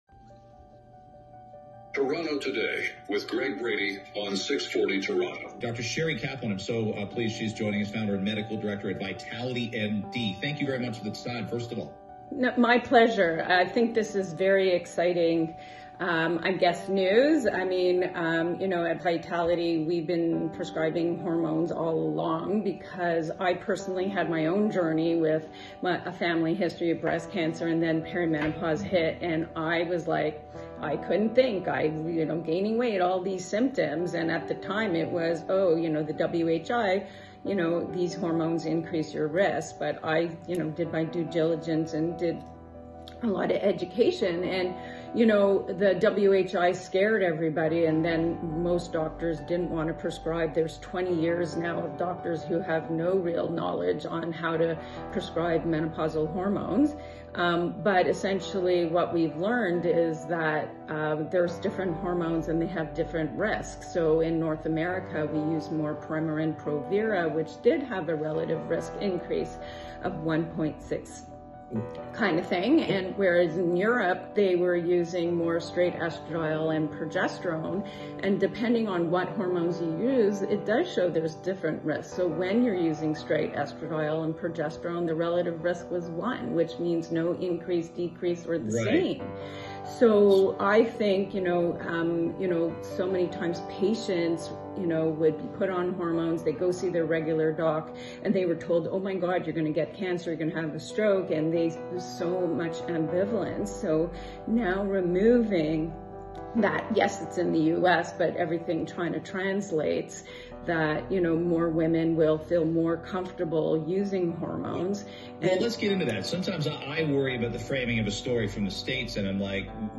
from Radio 640
This interview highlights: